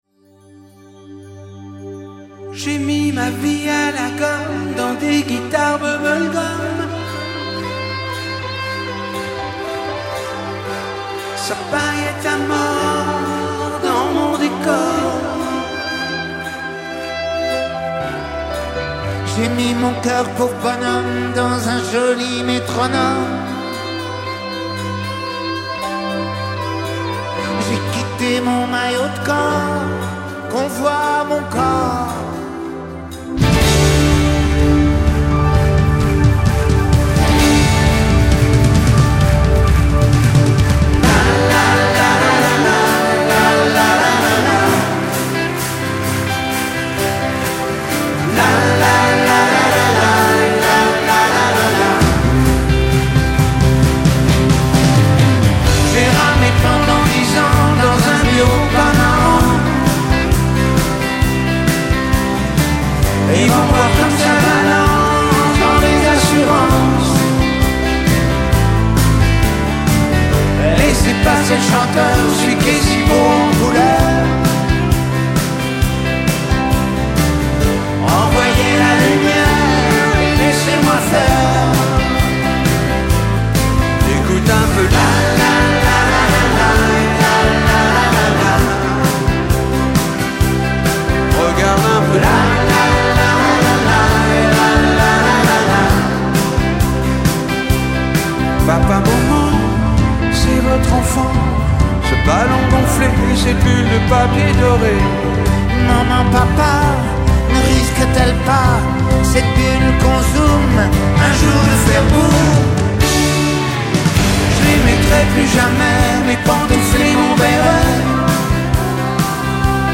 1978 tonalité SOL majeur